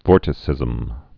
(vôrtĭ-sĭzəm)